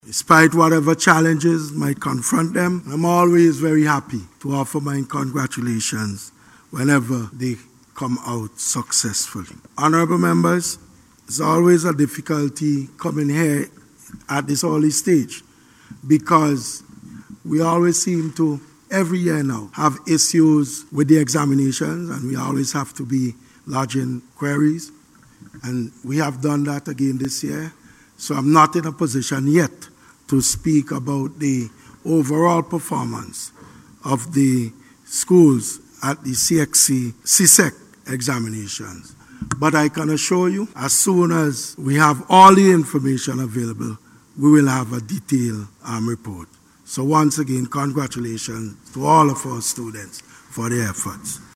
The Minister also congratulated the students for their hard work despite challenges that confronted them, during the congratulatory remarks in the House of Assembly earlier this morning.